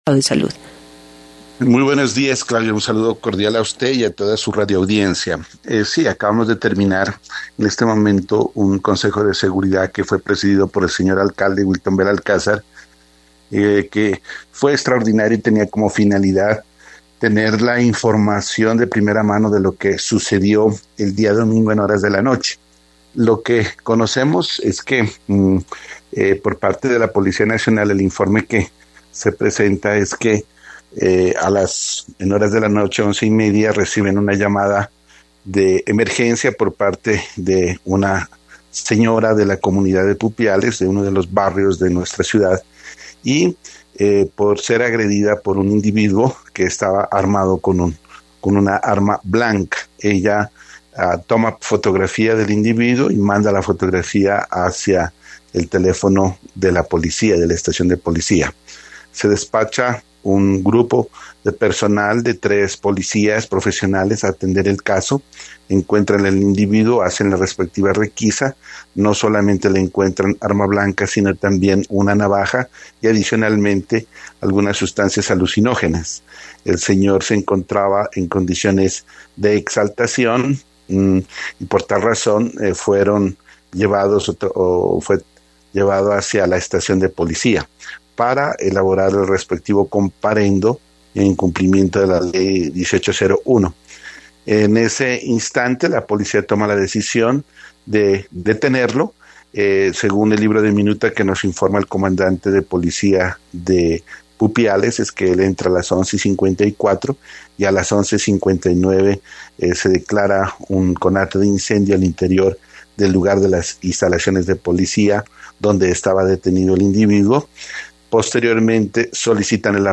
Mauricio Cisneros, secretario de Gobierno